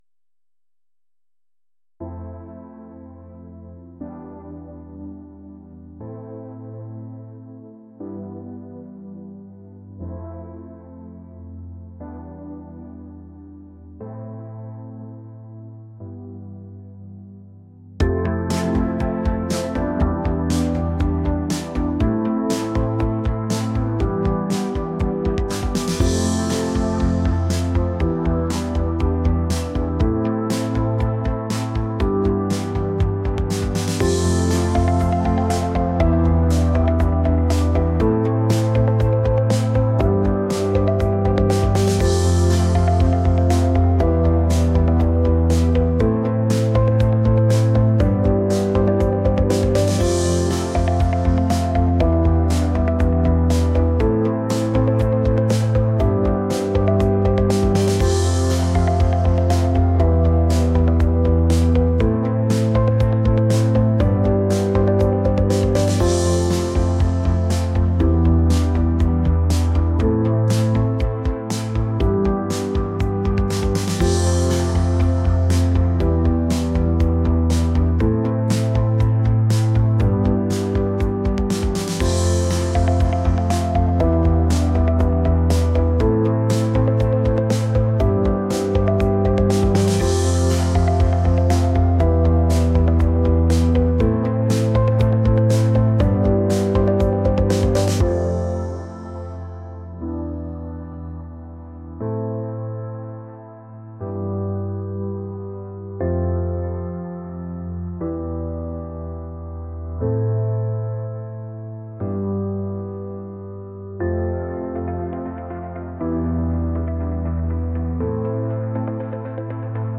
dreamy